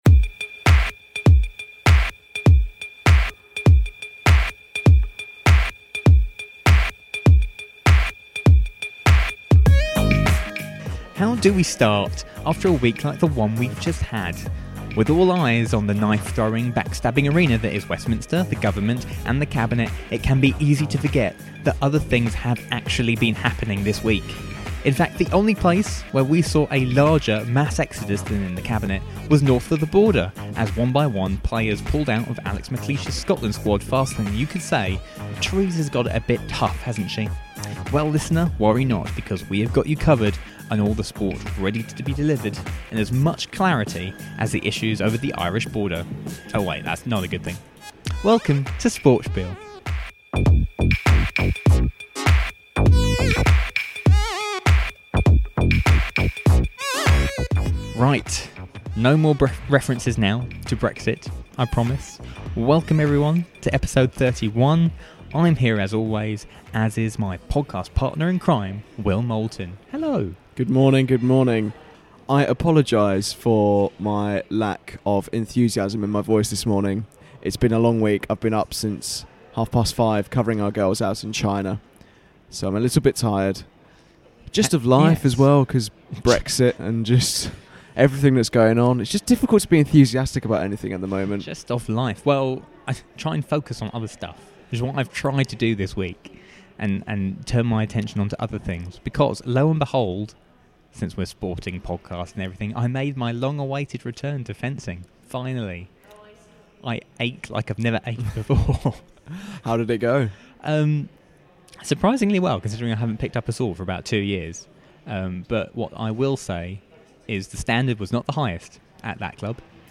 Feature Interview On our feature interview section